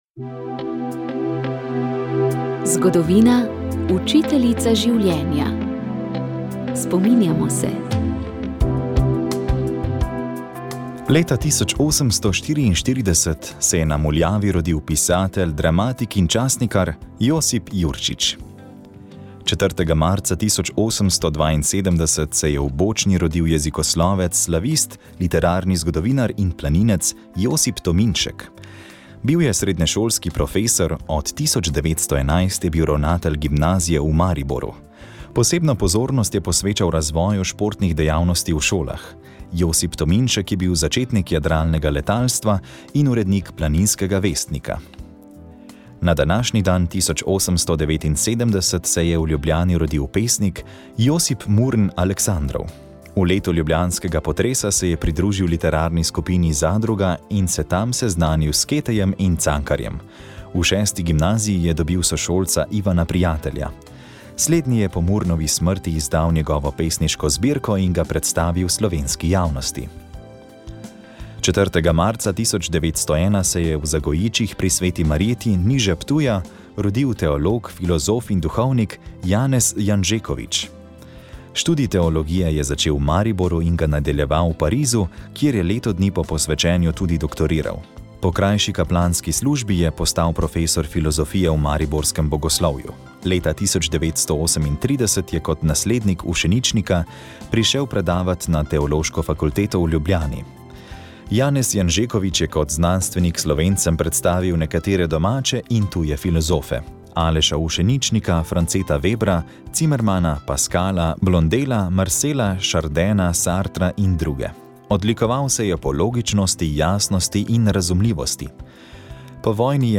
Sv. maša iz stolne cerkve sv. Nikolaja v Murski Soboti 3. 3.